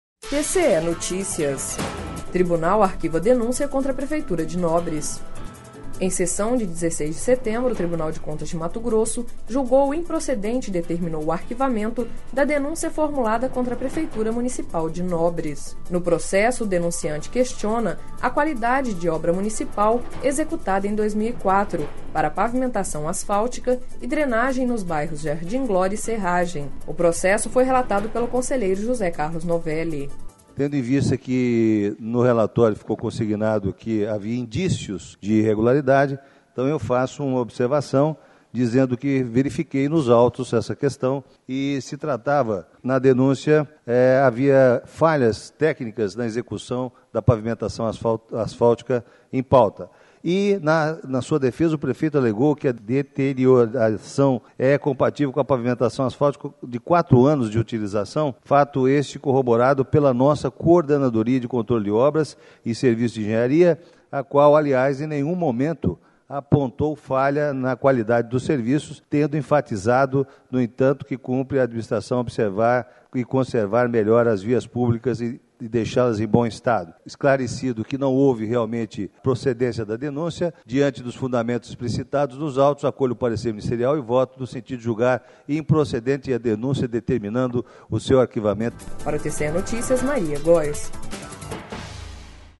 Sonora: José Carlos Novelli - conselheiro do TCE-MT